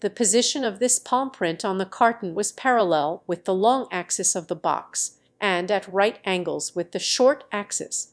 Matcha-TTS - [ICASSP 2024] 🍵 Matcha-TTS: A fast TTS architecture with conditional flow matching